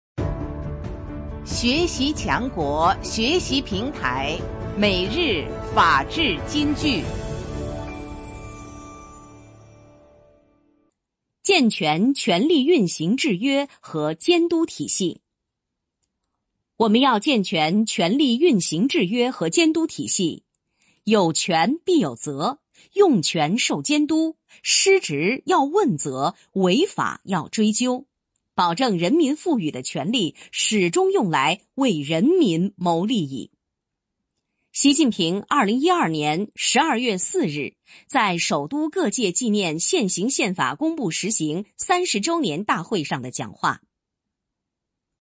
每日法治金句（朗读版）|健全权力运行制约和监督体系 _ 宪法 _ 福建省民政厅